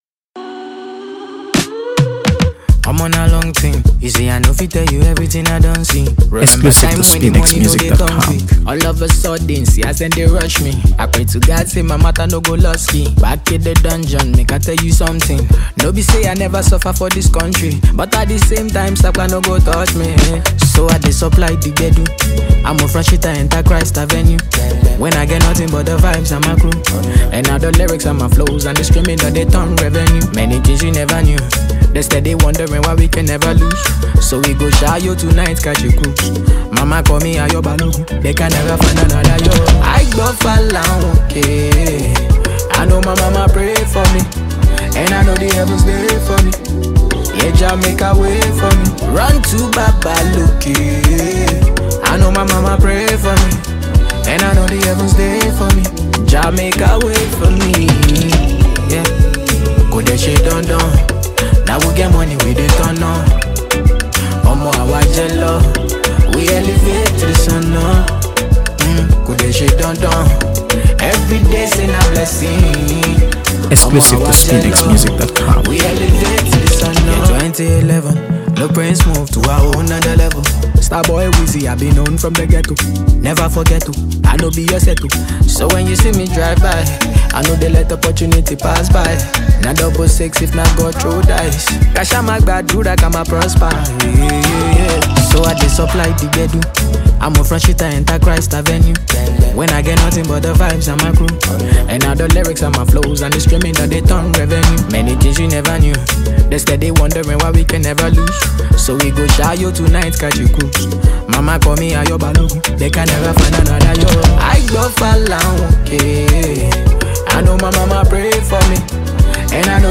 AfroBeats | AfroBeats songs
infectious Afrobeat rhythms